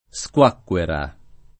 SkU#kkUera] (antiq. squacchera [